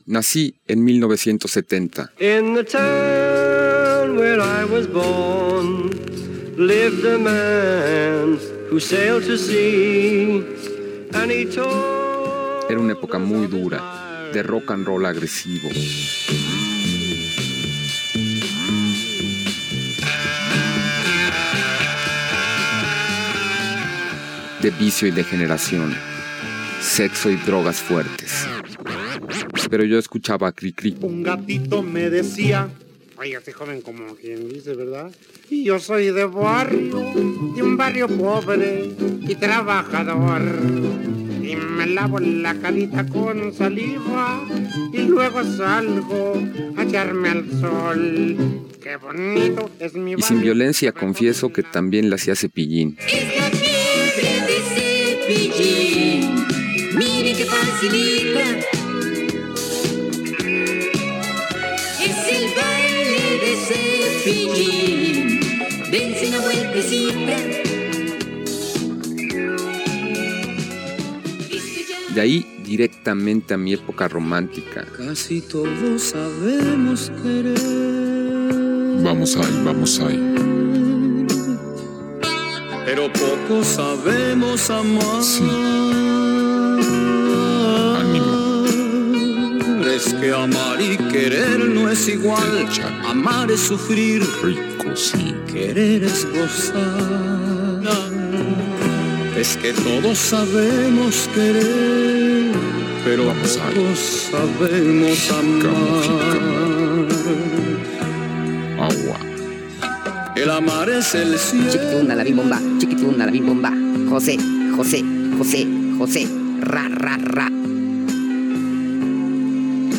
Gènere radiofònic